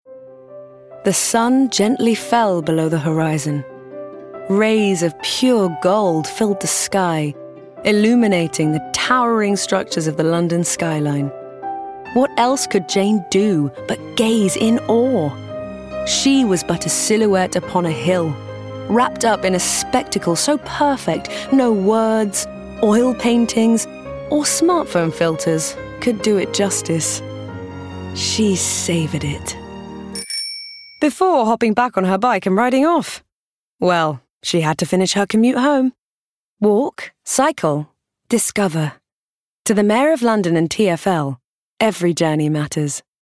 ***NEW ARTIST*** | 20s-30s | Transatlantic, Genuine & Charismatic
TFL (RP)